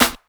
Snare_34.wav